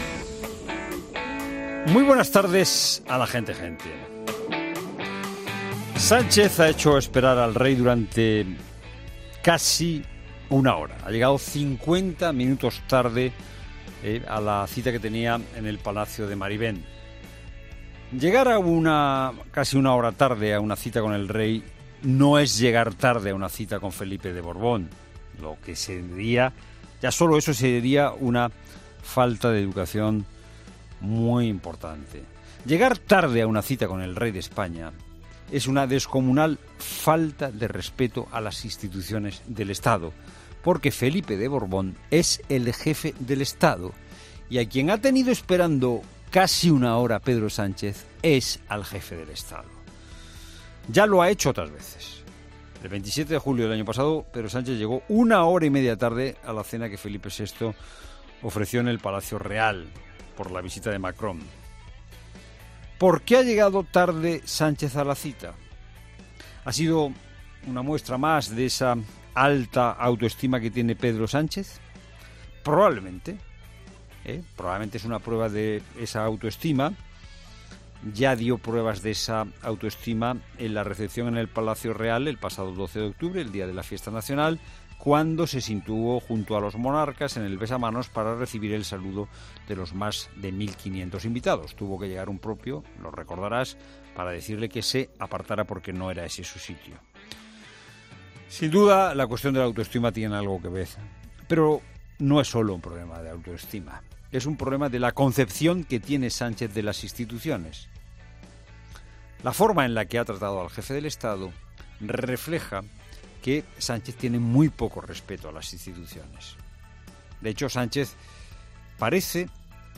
Monólogo de Fernando de Haro
El comunicador de 'La Tarde de COPE' analiza en su monólogo de las 16.00 horas la última polémica del presidente del Gobierno en funciones